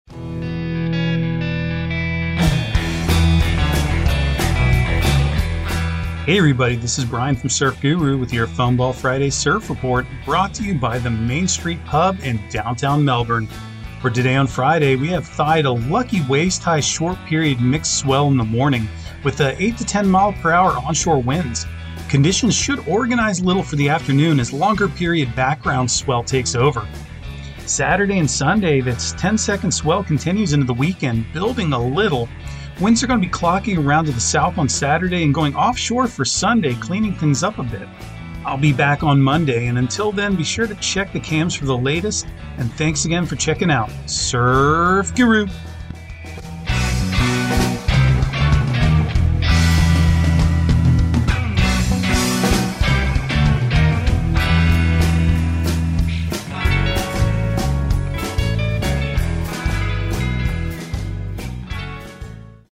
Surf Guru Surf Report and Forecast 12/30/2022 Audio surf report and surf forecast on December 30 for Central Florida and the Southeast.